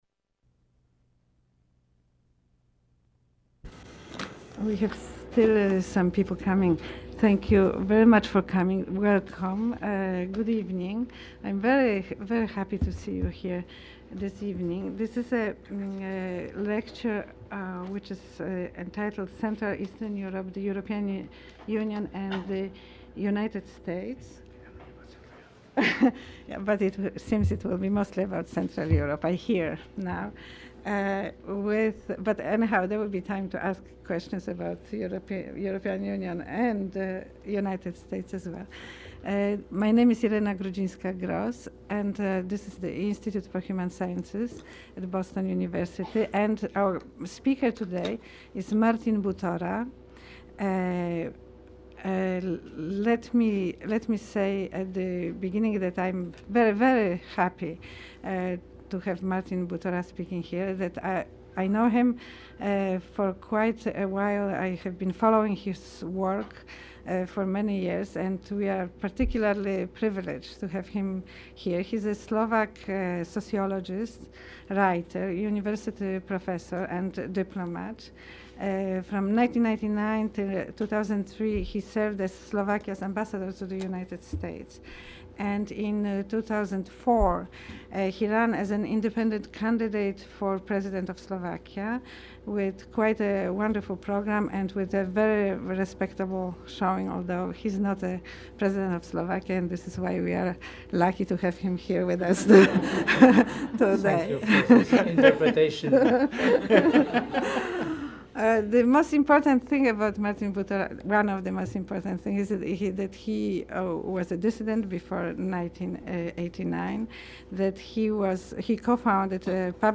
martin_butora_003.jpg (5.42 MB) Martin_Butora_Event.mp3 (76.95 MB) Audio recording of the talk Show more martin_butora_004.jpg (5.34 MB) martin_butora_005.jpg (5.81 MB) martin_butora_006.jpg (5.43 MB) Show more